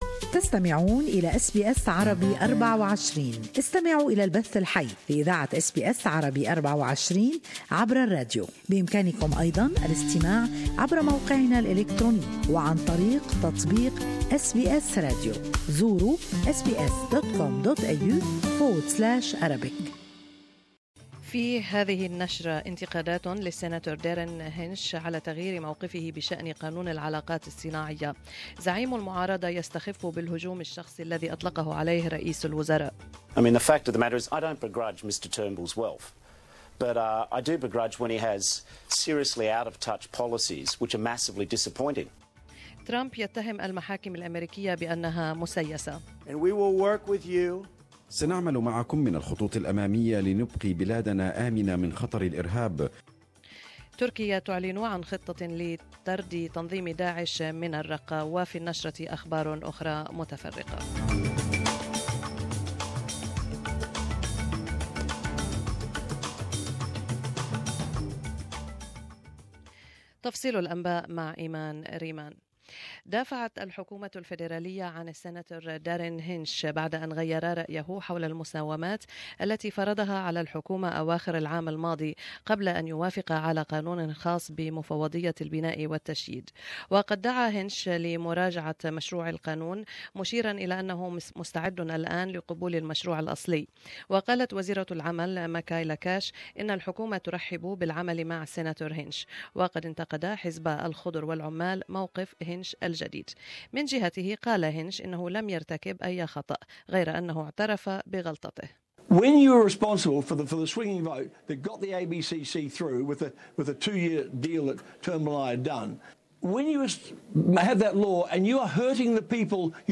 News Bulletin 9-2-17